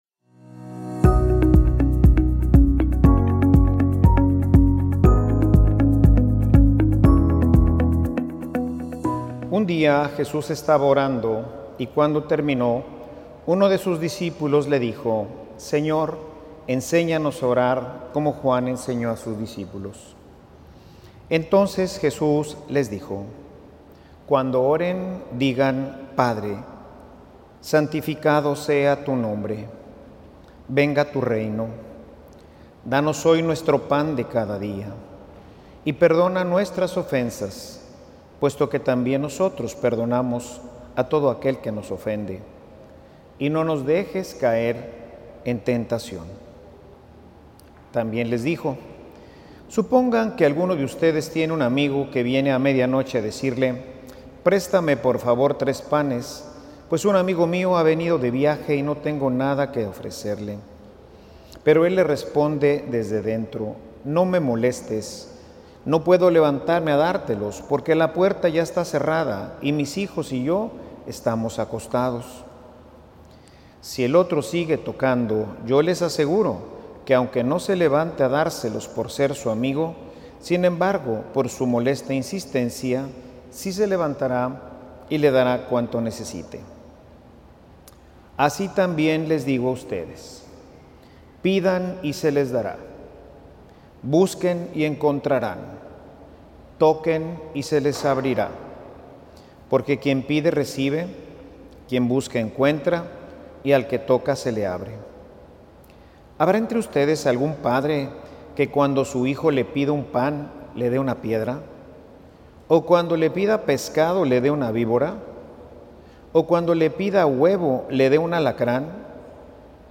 Homilia_aprendamos_a_pedir_al_padre.mp3